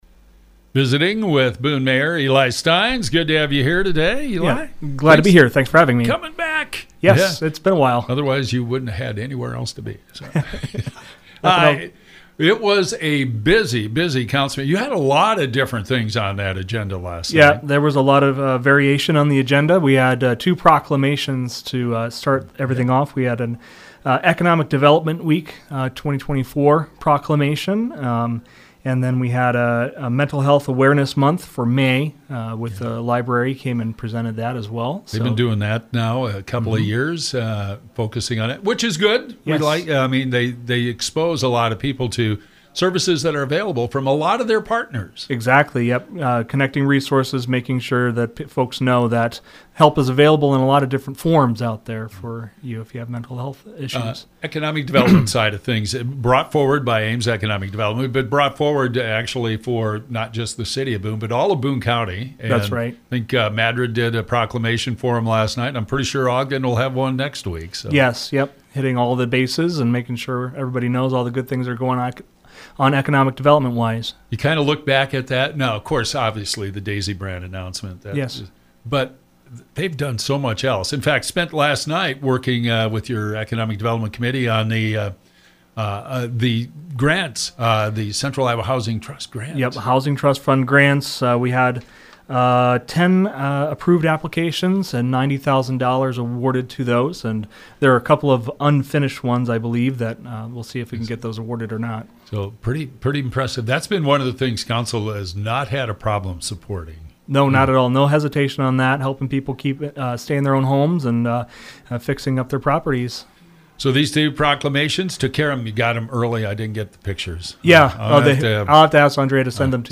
Eli Stines, Boone Mayor talks about Boone City Council Meeting. He talked about the Proclamations at the beginning of the meeting, the first for Economic Development Week this week and Mental Health Awareness Month this month. He talked about the Urban Chicken Ordinance which the Council tabled and referred to the Policy, Administration and Employee Relations Committee. Stines says he hopes the Council can take action at their first meeting in June.